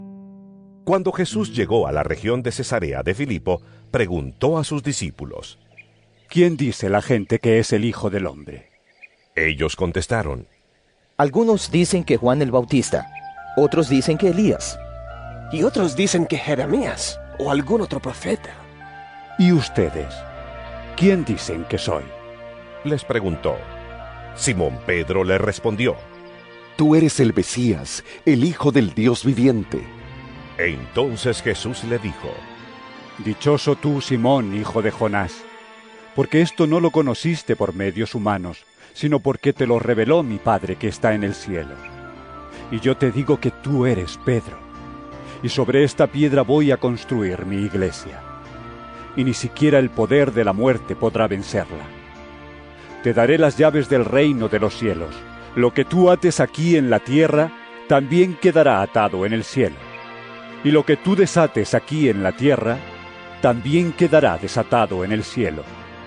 Mt 16 13-19 EVANGELIO EN AUDIO